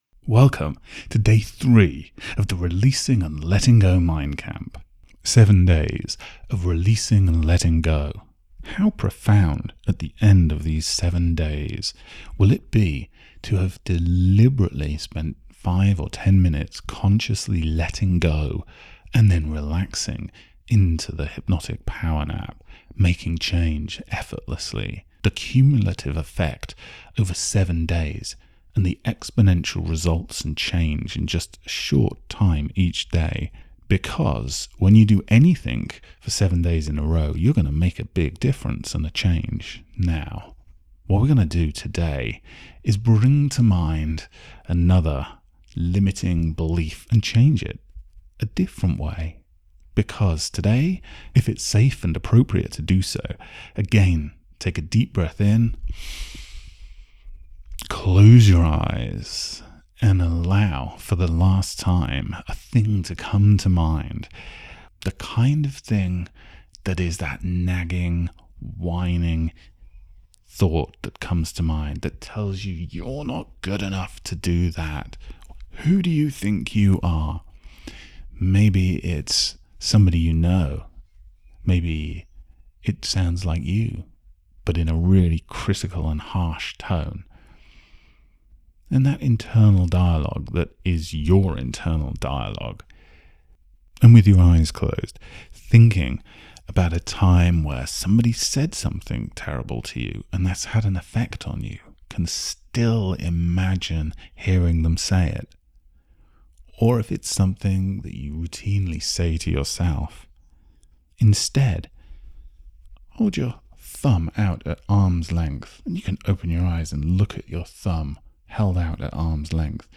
Its best to slip on a pair of headphones... get yourself comfortable, press play and relax with this 11 minute Hypnotic PowerNap for Releasing & Letting Go
Plus a guided meditation following the 3 Step SoBeing process.